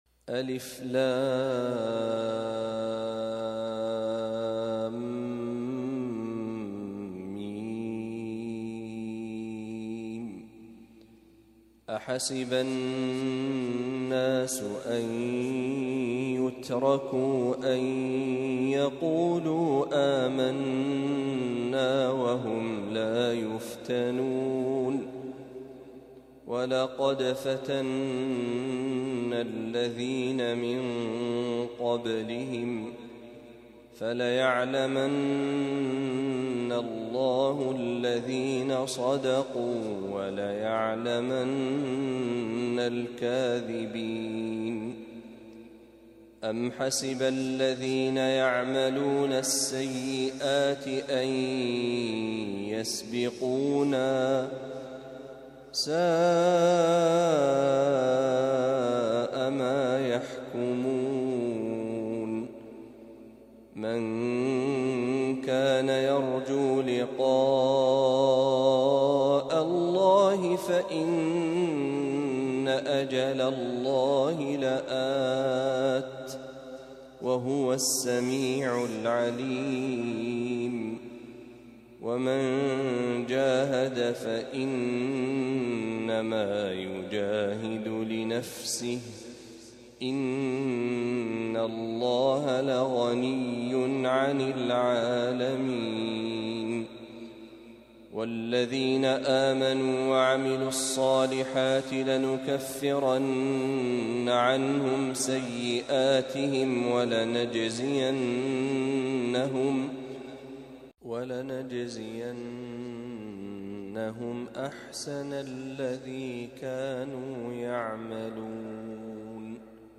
تلاوة لفواتح سورة العنكبوت